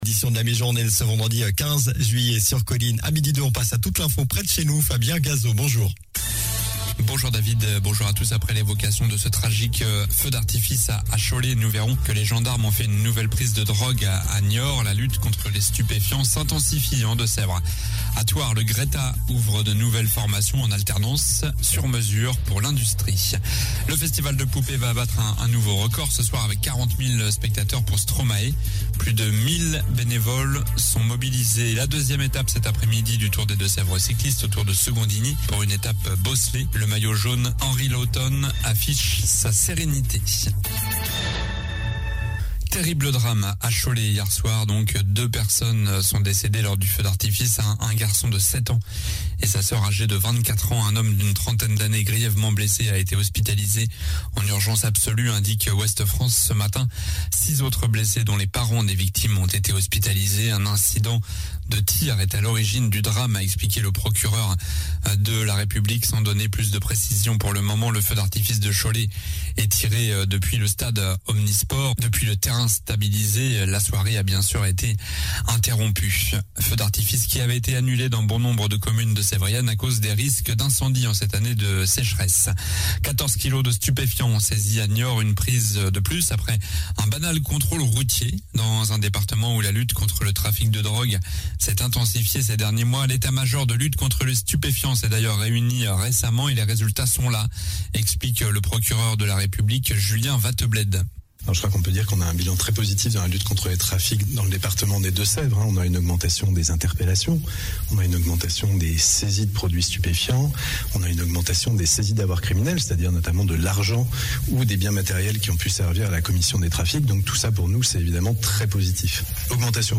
Journal du vendredi 15 juillet (midi)